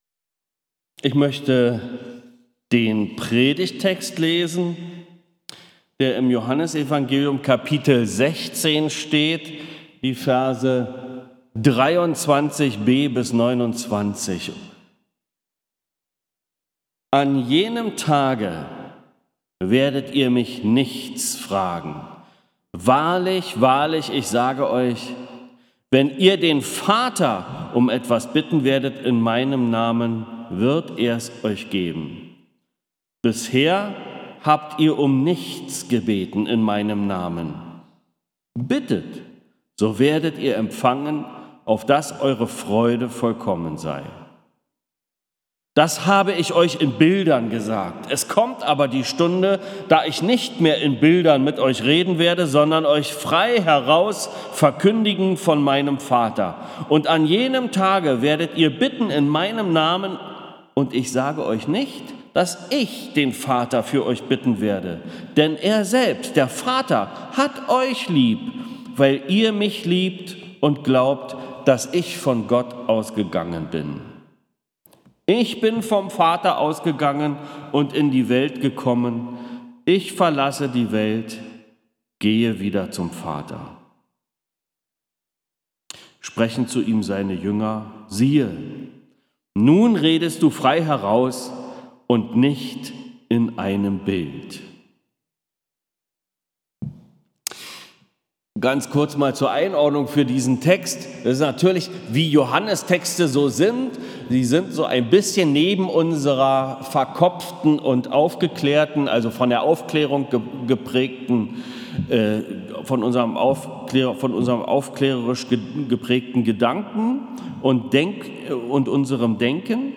Predigten | Bethel-Gemeinde Berlin Friedrichshain